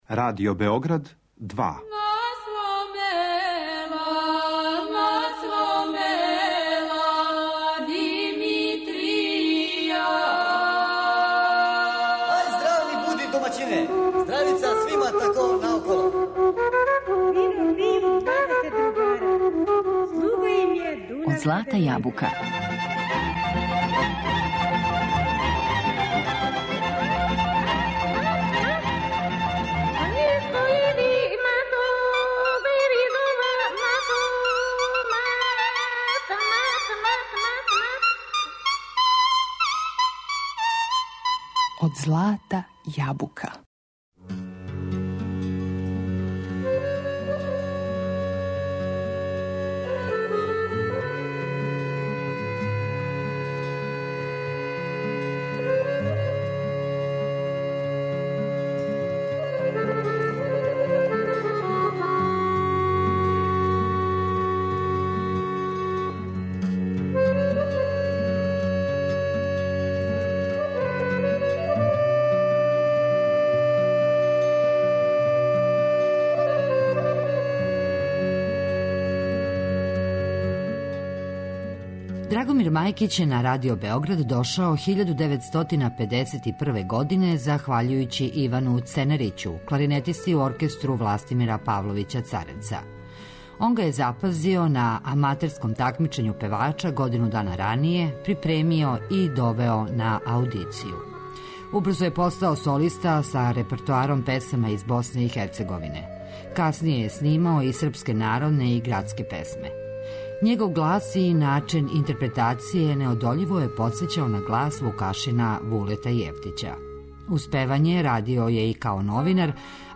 Интервју забележен 2009. године.